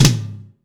ROOM TOM2B.wav